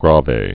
(grävā)